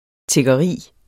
Udtale [ tegʌˈʁiˀ ]